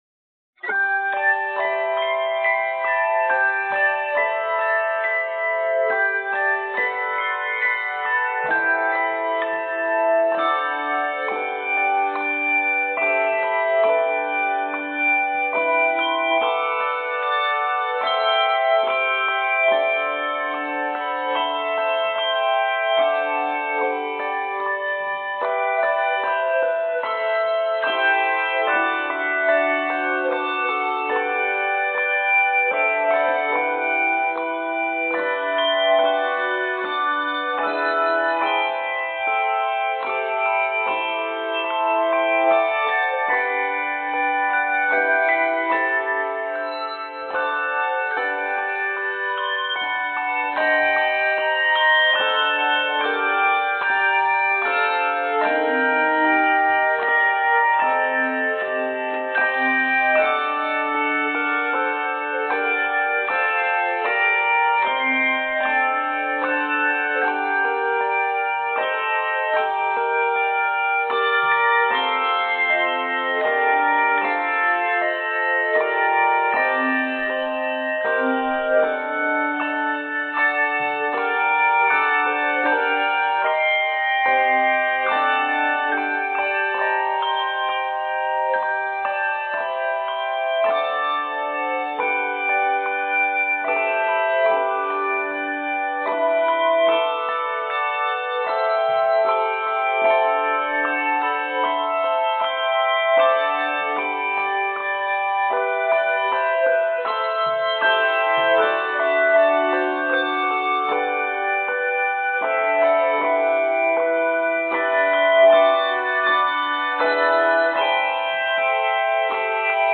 Key changes add interest.